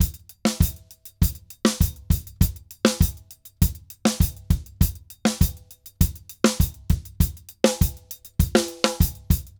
Drums_Samba 100_2.wav